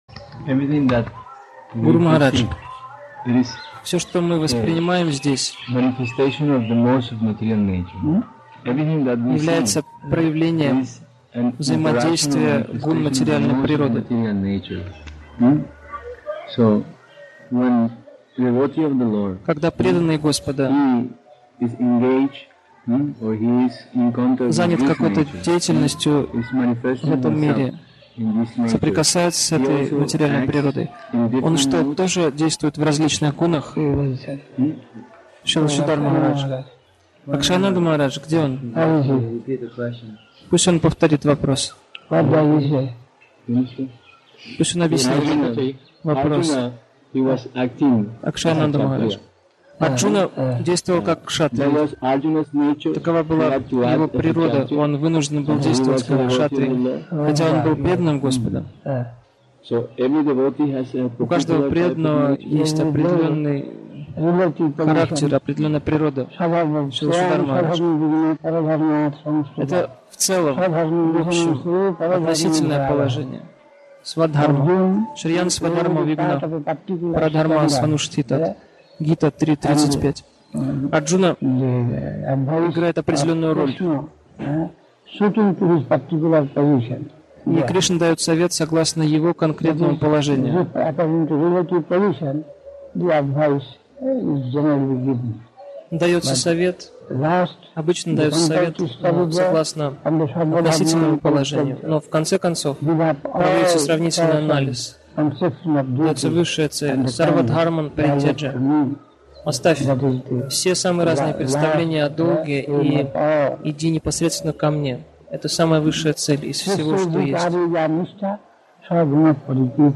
Навадвипа Дхама, Индия)